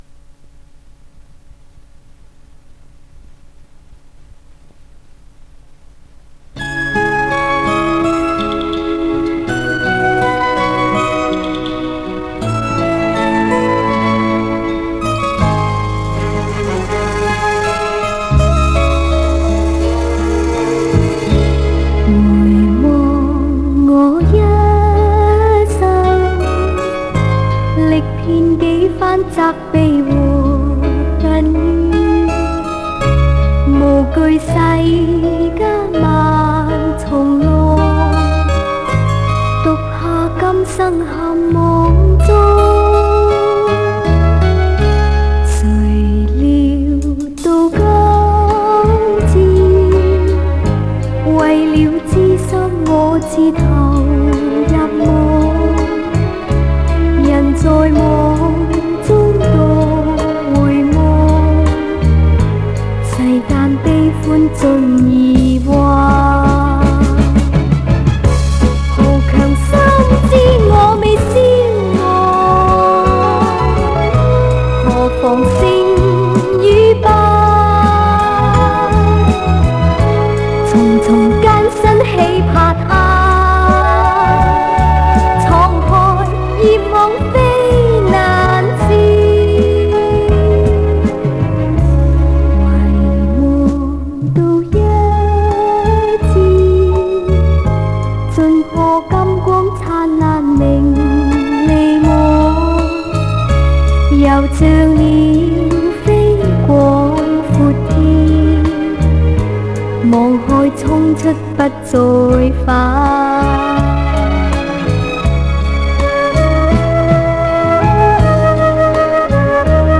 磁带数字化：2022-09-04